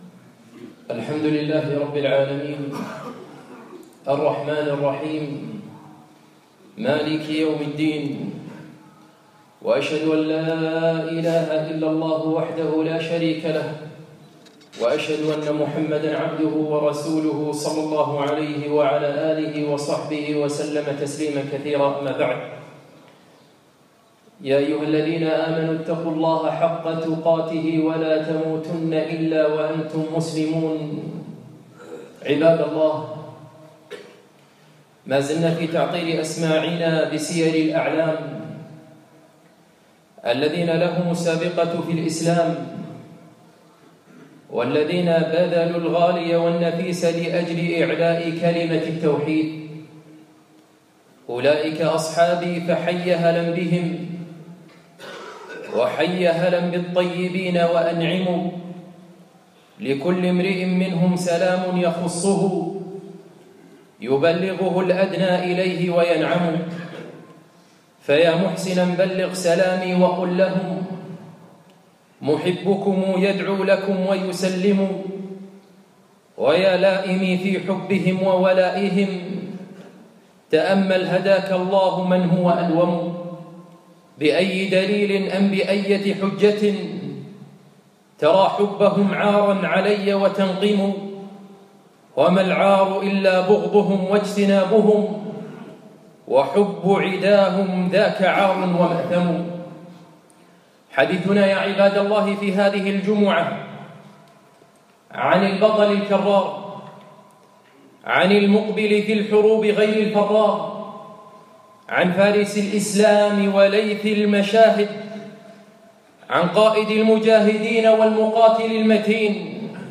يوم الجمعة 3 3 2017 مسجد ضاحية الفردوس